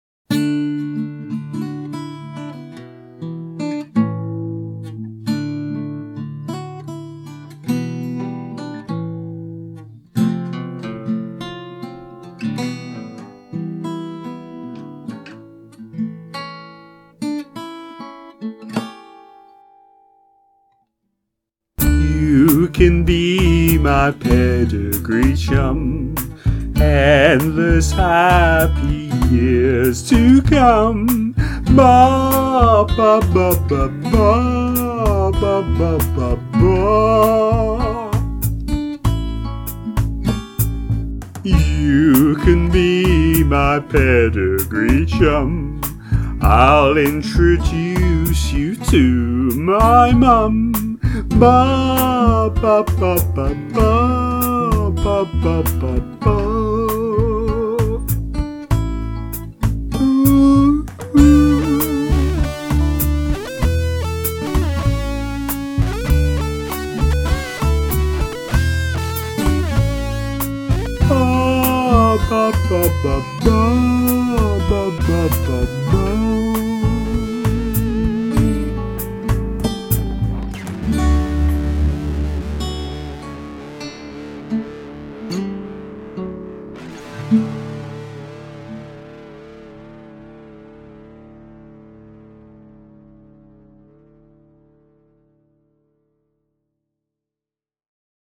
beautiful ballad
tender warbling